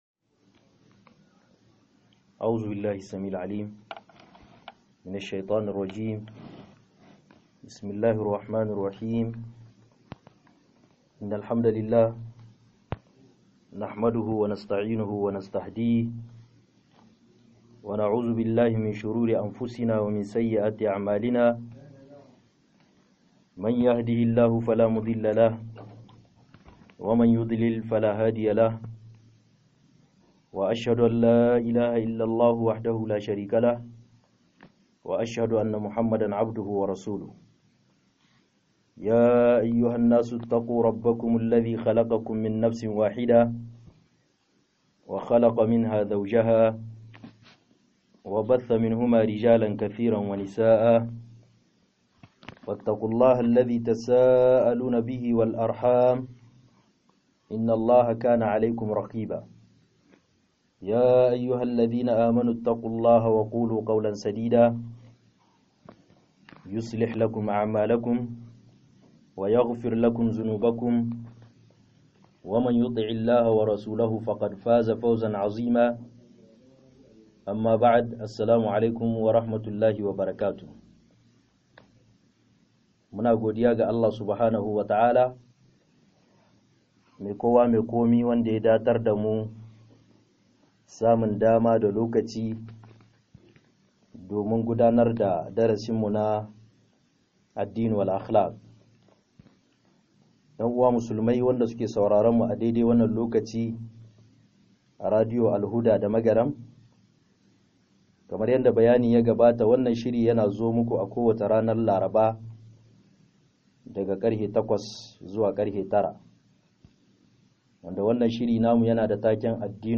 05 ADDINI-DA-HALAYE-MASU-KEW - MUHADARA